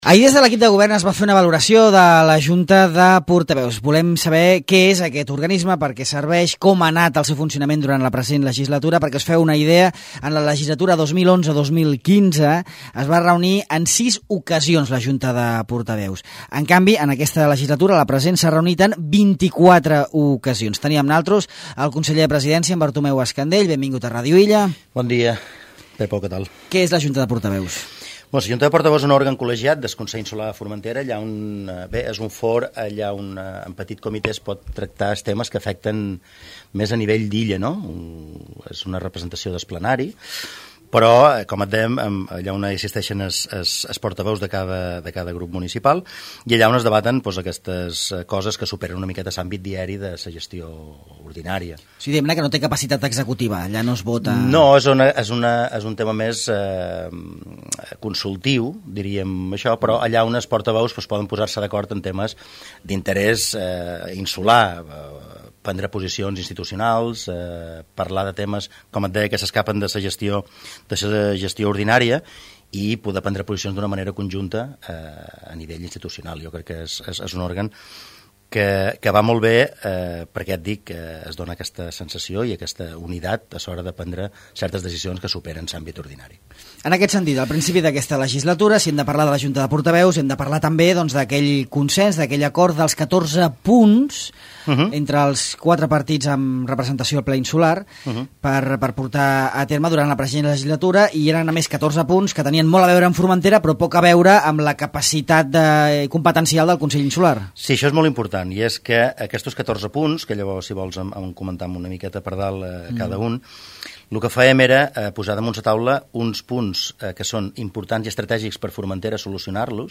El conseller de Presidència, Bartomeu Escandell, fa un repàs a Ràdio Illa dels 14 punts consensuats amb la resta de forces polítiques de Formentera a principis de la legislatura que ara acaba.